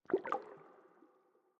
Minecraft Version Minecraft Version snapshot Latest Release | Latest Snapshot snapshot / assets / minecraft / sounds / ambient / underwater / additions / driplets1.ogg Compare With Compare With Latest Release | Latest Snapshot
driplets1.ogg